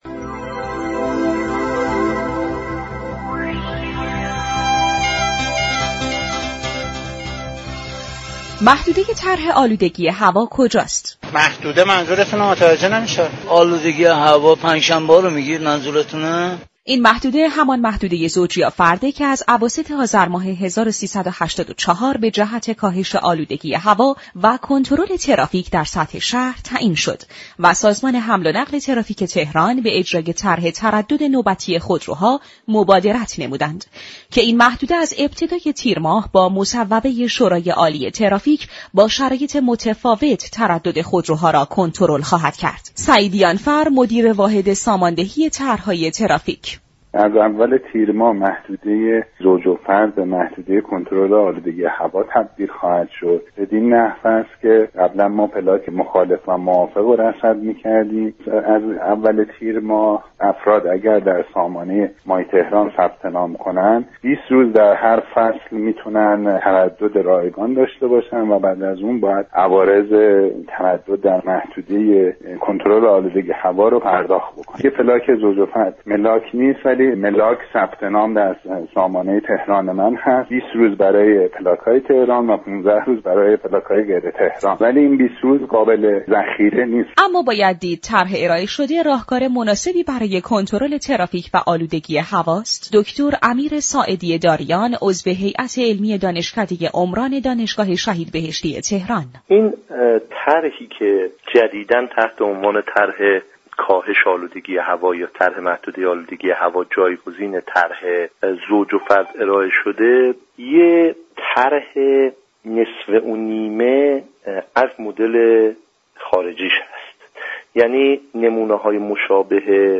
در آیتم گزارشگری